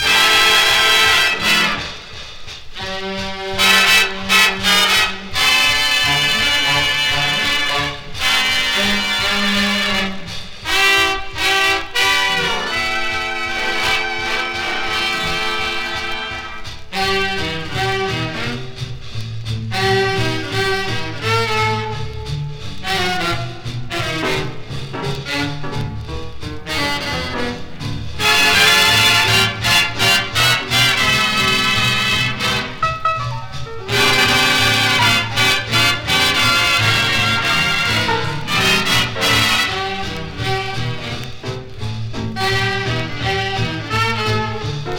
Jazz, Blues, Swing, Big Band　USA　12inchレコード　33rpm　Mono